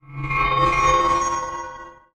magic.ogg